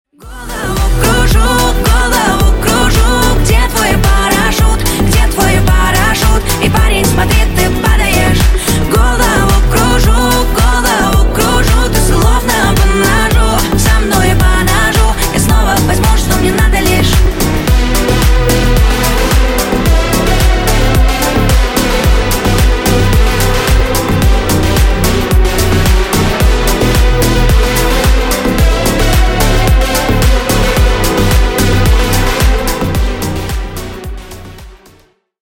Громкие Рингтоны С Басами
Поп Рингтоны